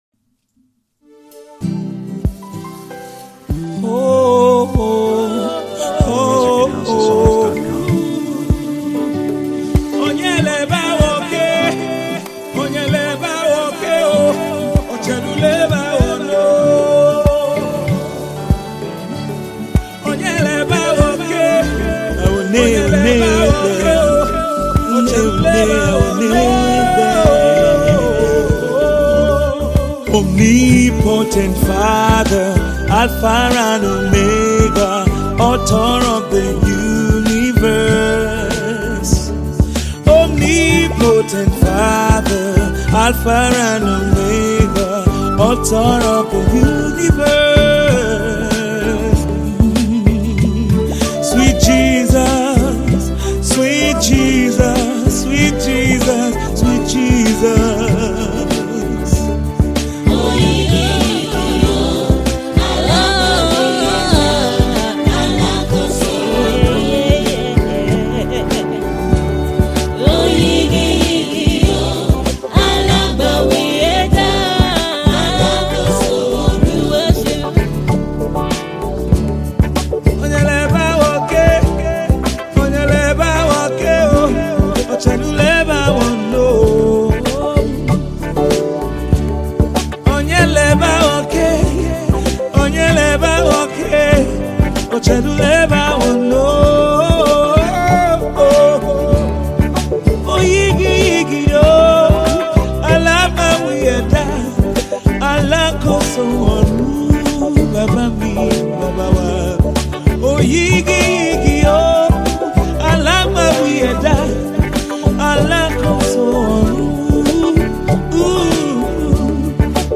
Tiv songs
encouraging, uplifts the spirit and soul